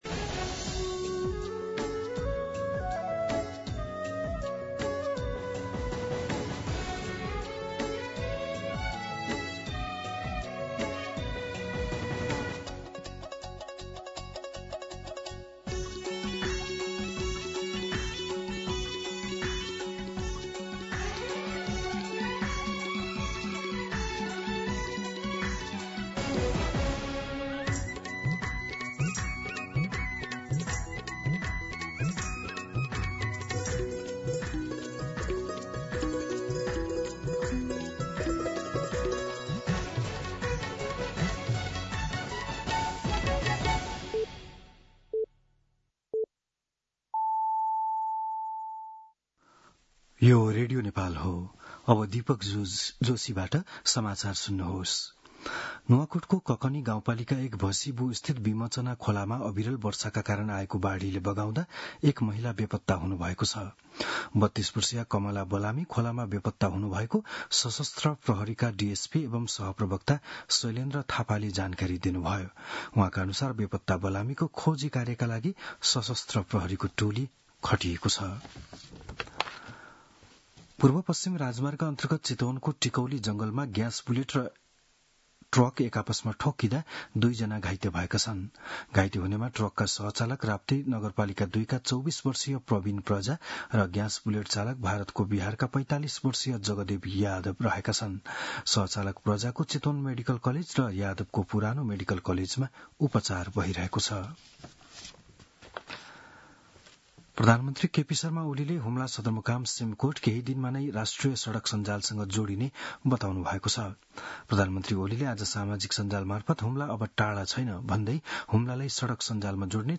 बिहान ११ बजेको नेपाली समाचार : १३ असार , २०८२